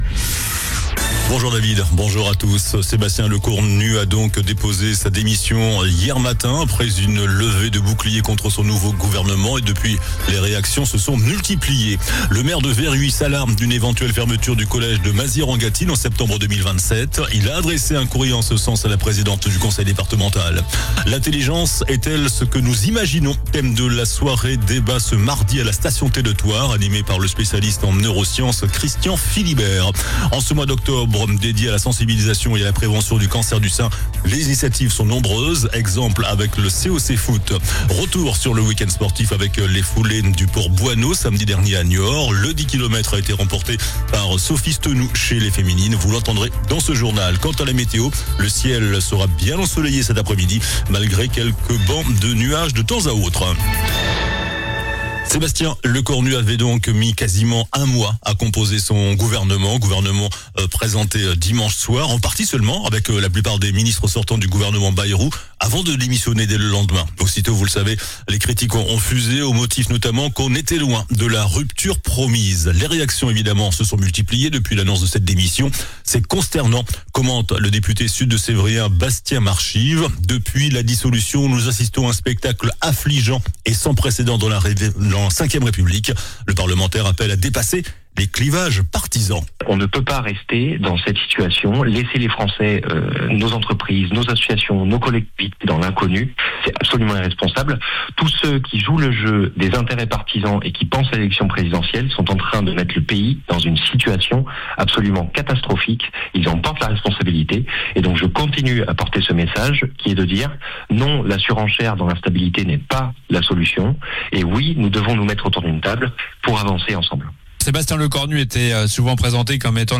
JOURNAL DU MARDI 07 OCTOBRE ( MIDI )